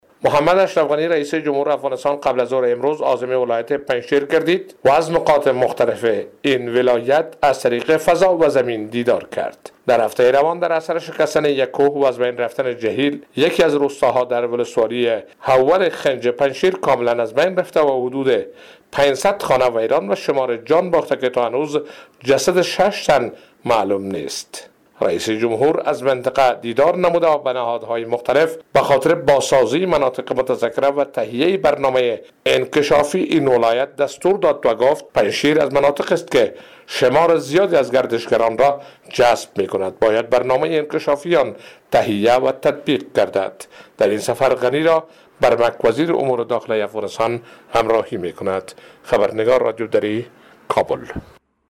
به گزارش خبرنگار رادیو دری،غنی ضمن بازدید هوایی از مناطق آسیب‌دیده ناشی از رانش کوه،با مردم ولایت پنجشیر دیدار صمیمانه‌ای خواهد داشت.